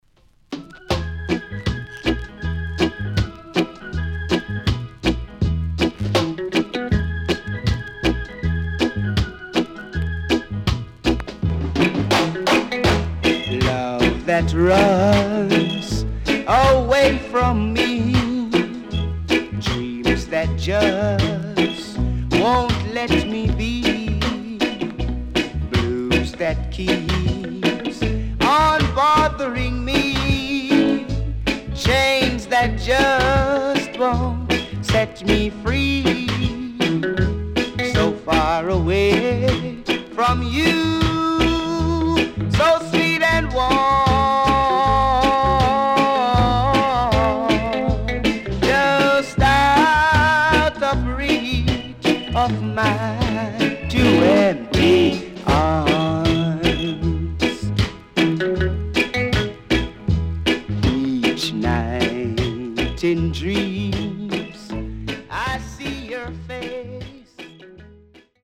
HOME > Back Order [VINTAGE 7inch]  >  EARLY REGGAE
CONDITION SIDE A:VG(OK)
W-Side Good Early Reggae
SIDE A:所々ノイズ入ります。